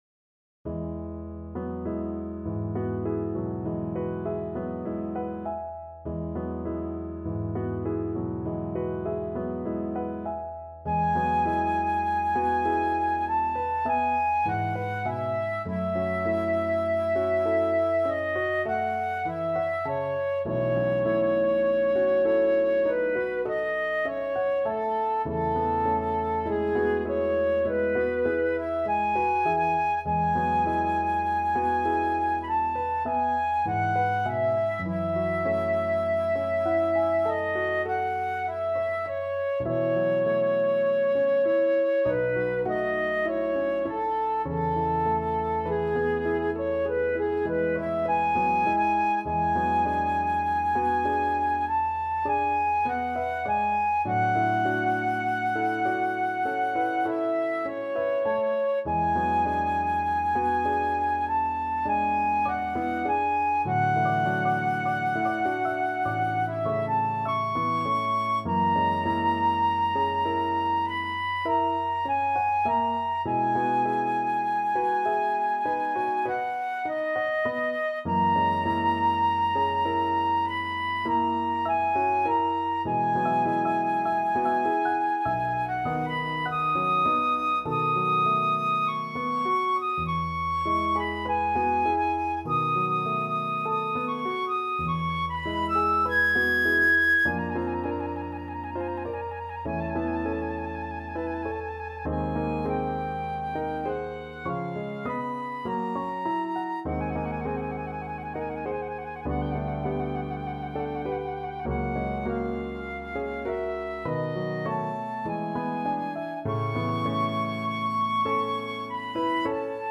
Free Sheet music for Flute
E major (Sounding Pitch) (View more E major Music for Flute )
Lento =50
4/4 (View more 4/4 Music)
Flute  (View more Intermediate Flute Music)
Classical (View more Classical Flute Music)